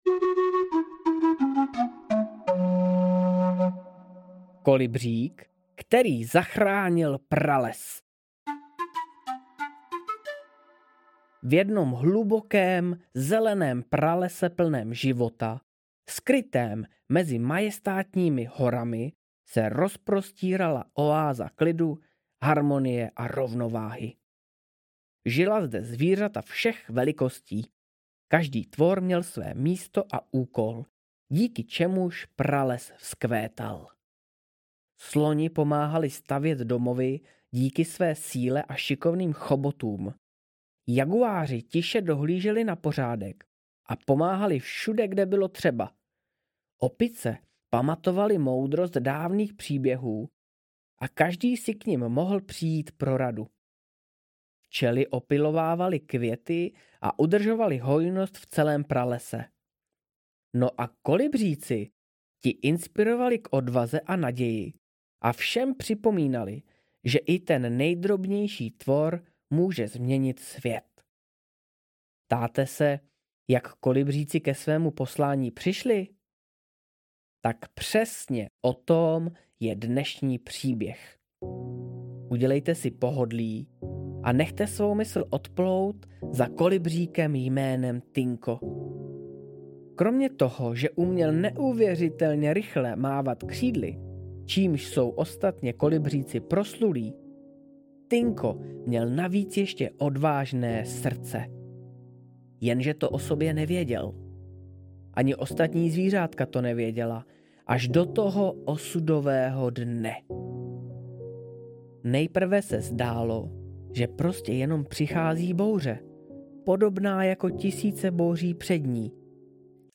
Příběhy ZeMě audiokniha
Ukázka z knihy